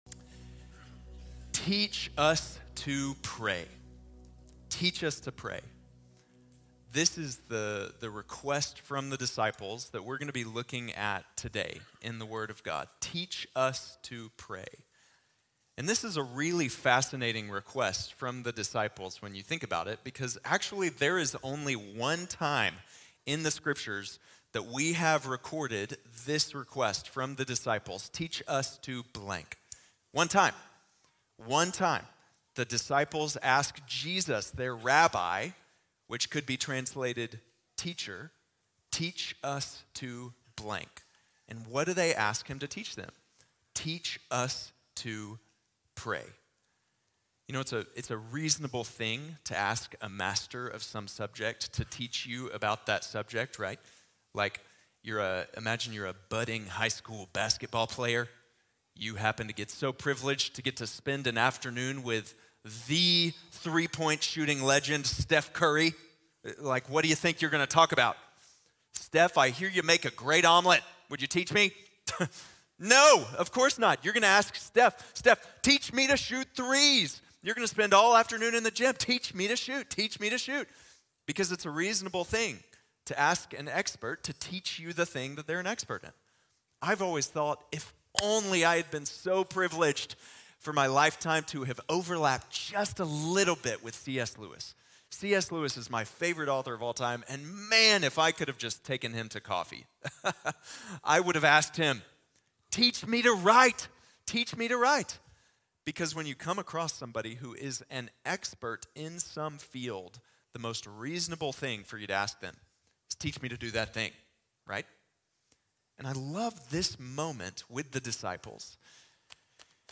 Enséñanos a Orar | Sermón | Iglesia Bíblica de la Gracia